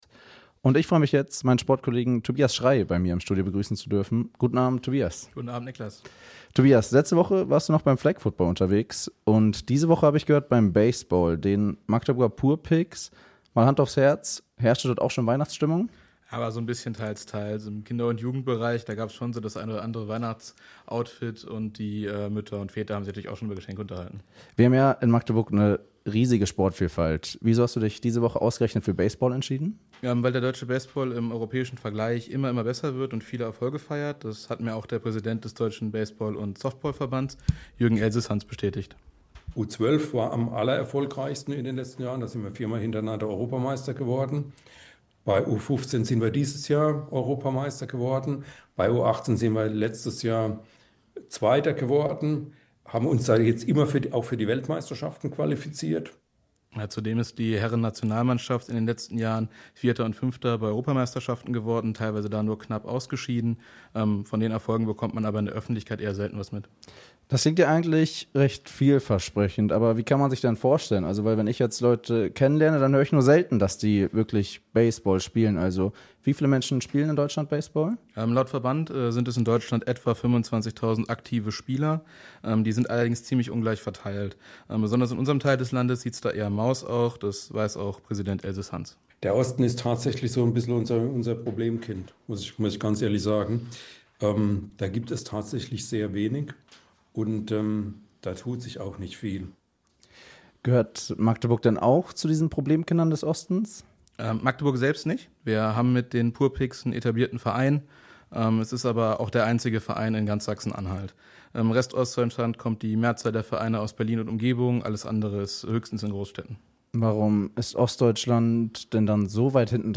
Kollegengespraech-Baseball.mp3